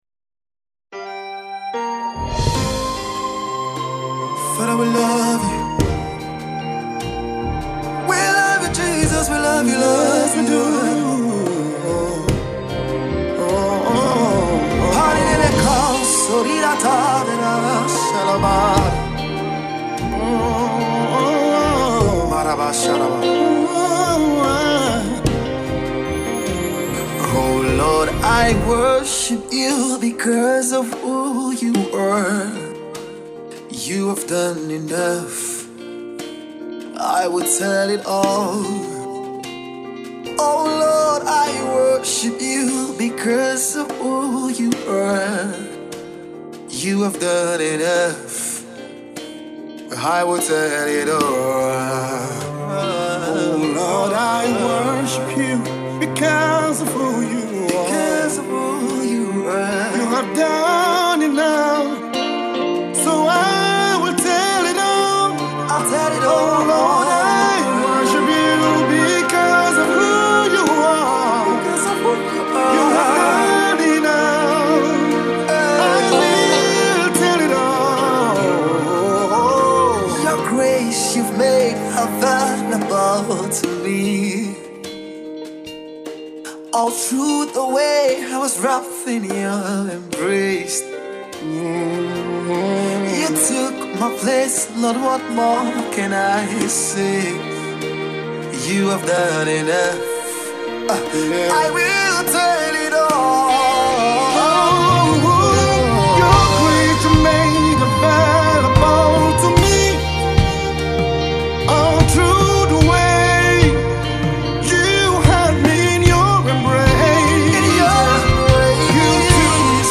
lyrical worship Single